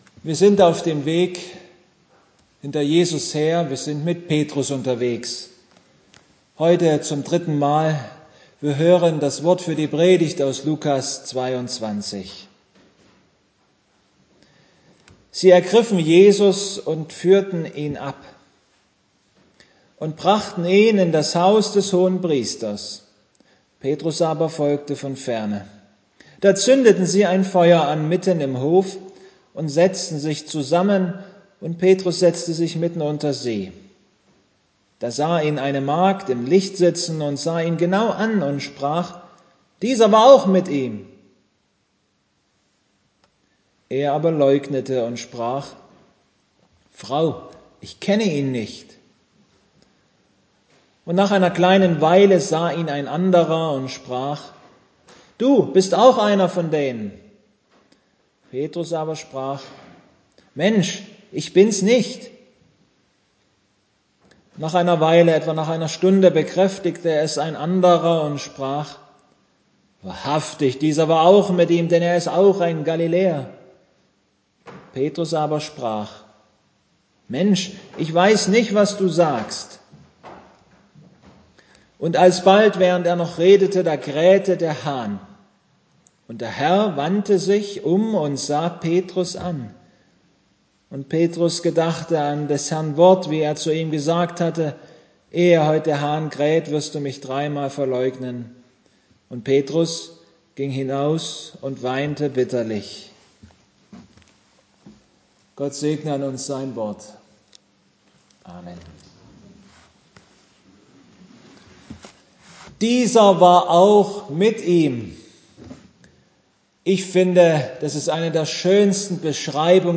Lk 22,54-62 Gottesdienstart: Predigtgottesdienst In dieser Passionszeit machen wir uns mit Petrus auf den Weg.